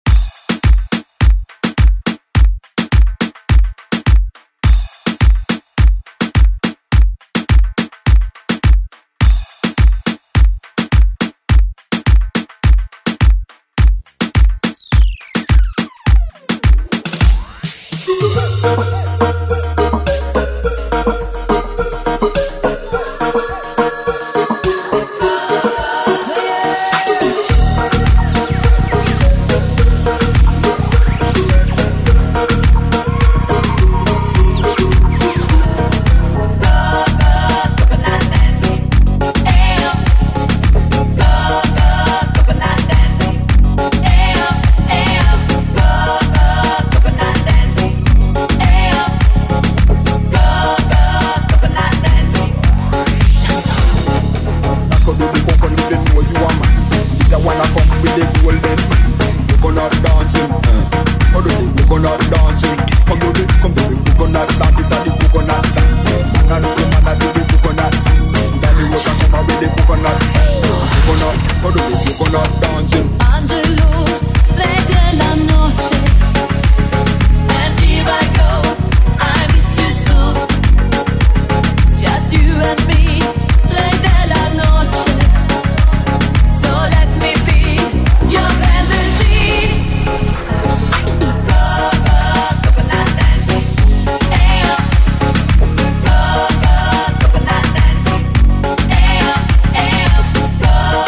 Genre: HIPHOP
Dirty BPM: 84 Time